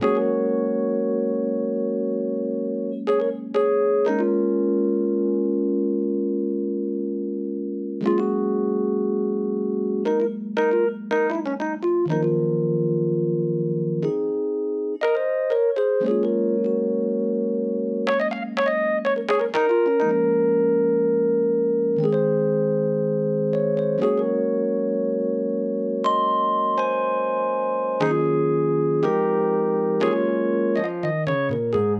13 rhodes B.wav